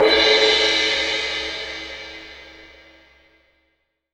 Index of /90_sSampleCDs/AKAI S6000 CD-ROM - Volume 3/Crash_Cymbal2/MALLET_CYMBAL